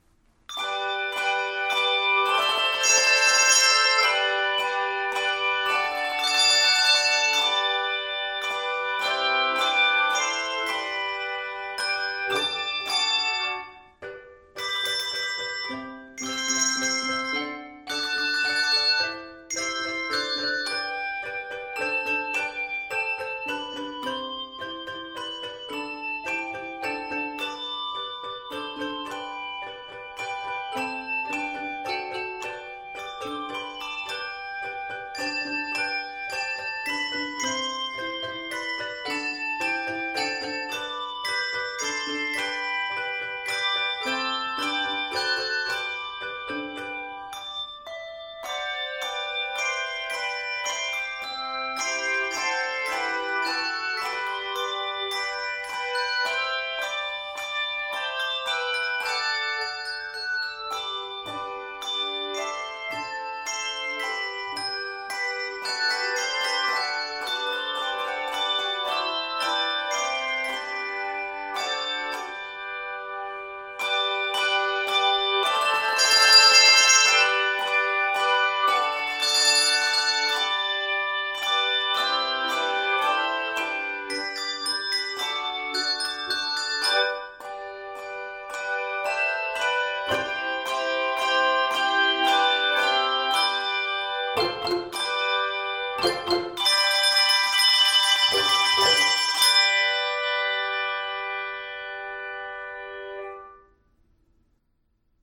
including various stopped techniques, shakes and echoes.